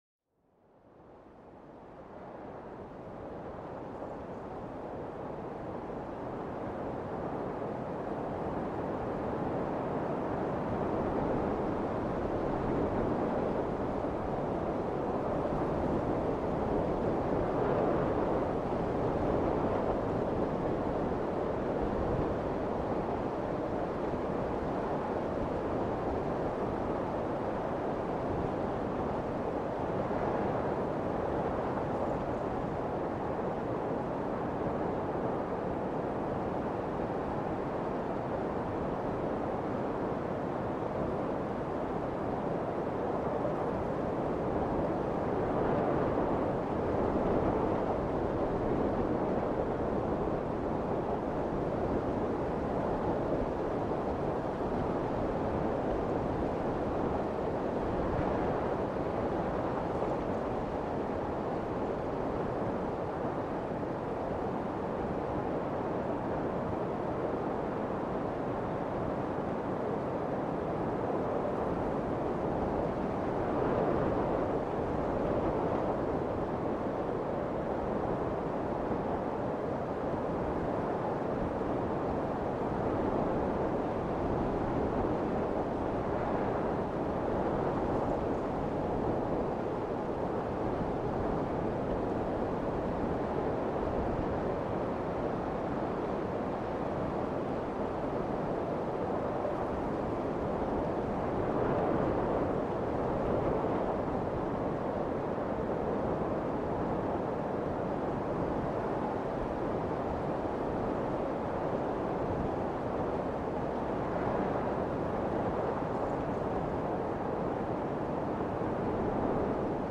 Plongez au cœur d'une forêt enchantée, où le doux murmure du vent entre les arbres évoque une mélodie apaisante.